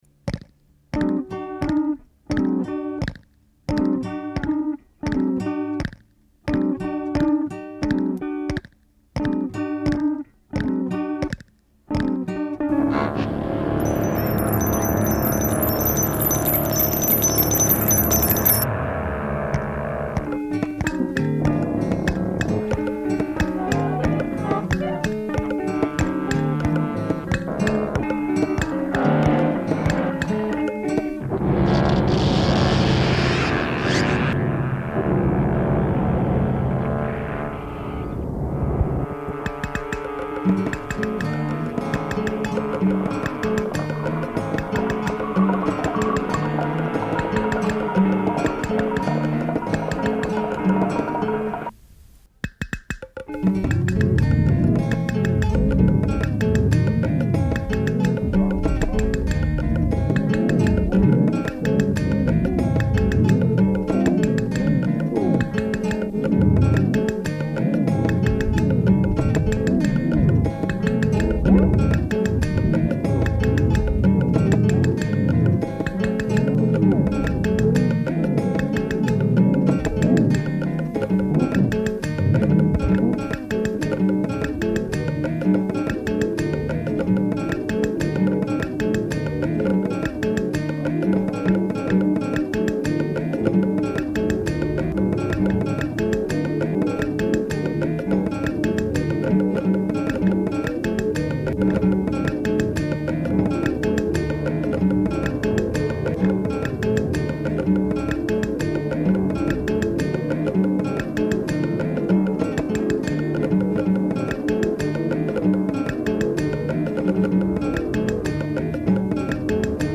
pour guitares, petites percussions,
instruments et sons divers
réalisé sur Revox A700
et A77 deux pistes